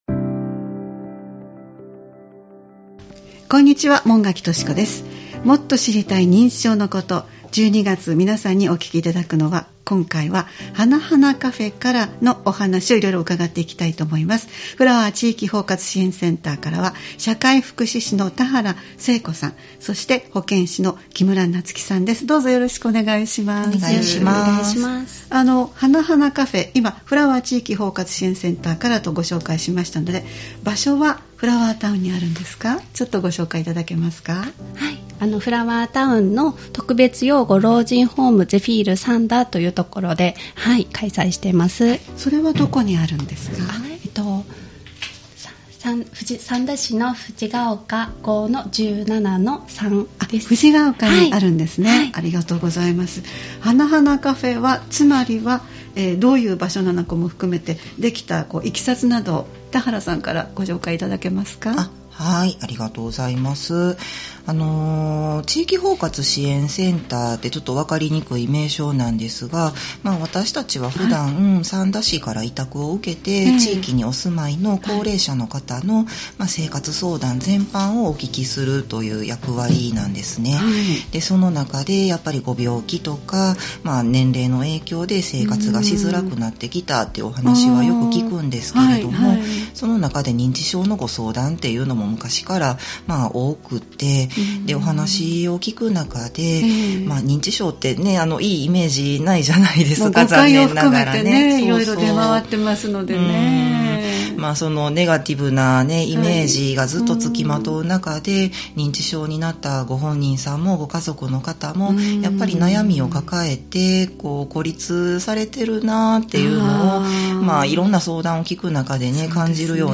毎月第1月曜日に配信するポッドキャスト番組「もっと知りたい認知症のこと」 スタジオに専門の方をお迎えして、認知症に関連した情報、認知症予防の情報、介護や福祉サービスなどを紹介していただきます（再生ボタン▶を押すと番組が始まります）